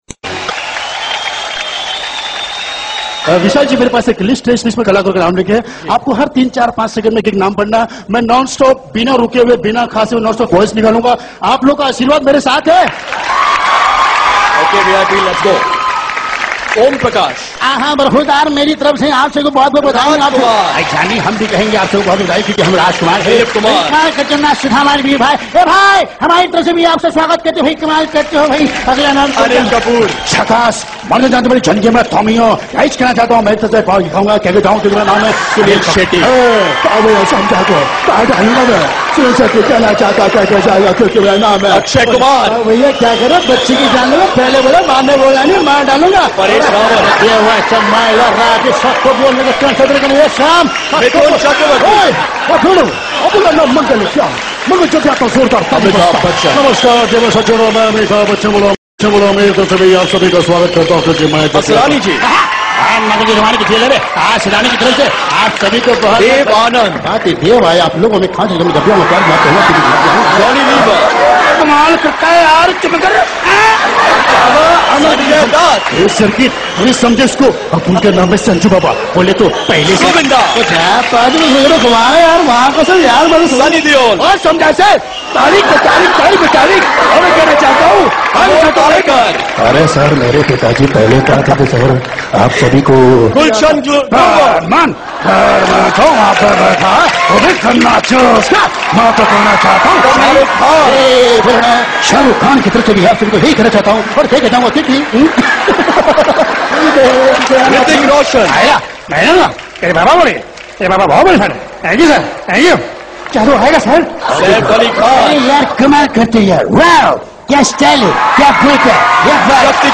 HomeMp3 Audio Songs > Whatsapp Audios > Funny Mimicry Voice